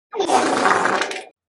35. Большой пук